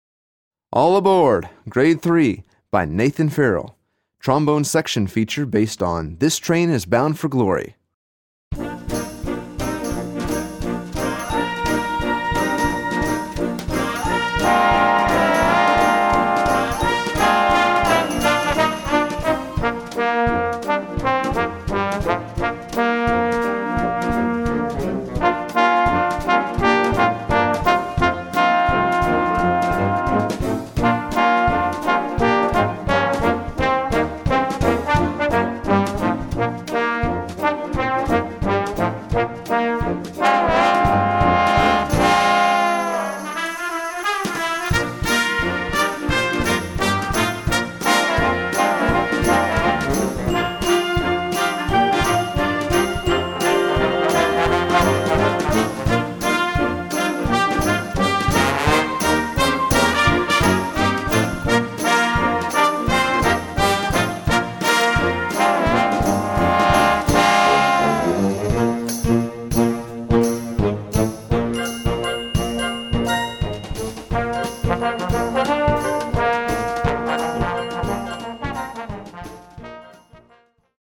Voicing: Trombone Section w/ Band